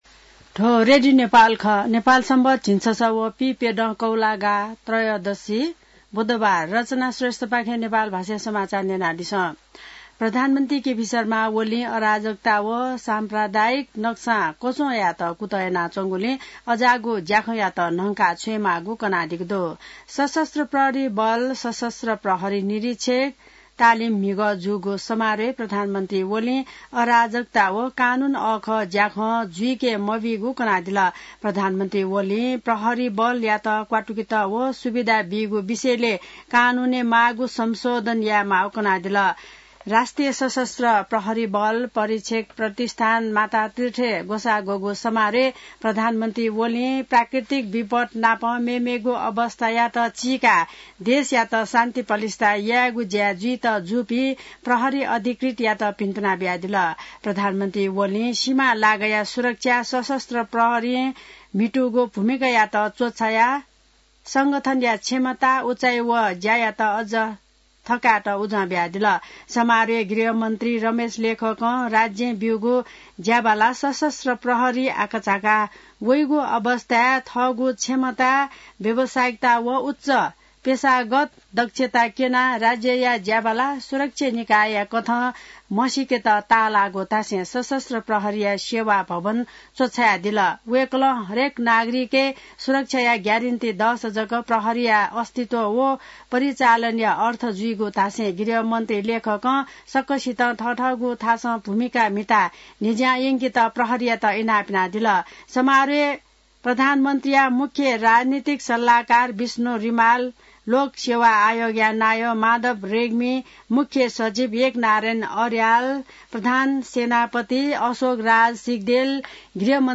नेपाल भाषामा समाचार : १५ कार्तिक , २०८१